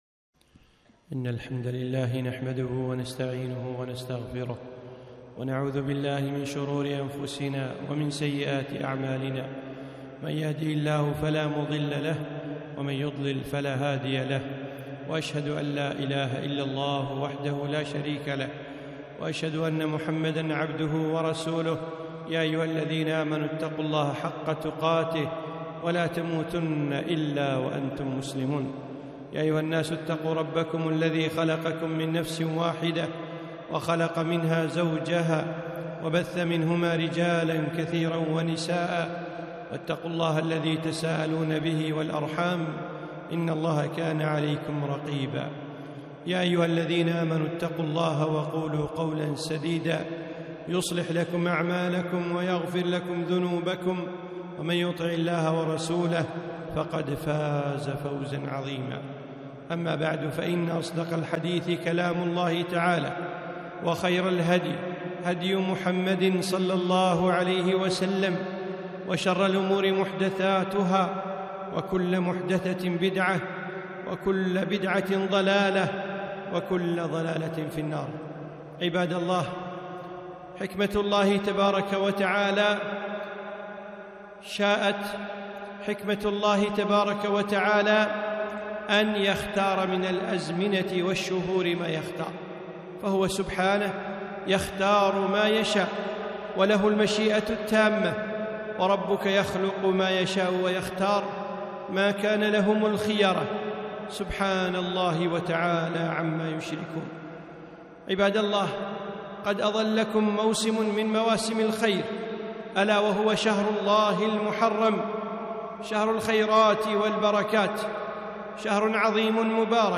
خطبة - محرم وعاشوراء 4-1-1443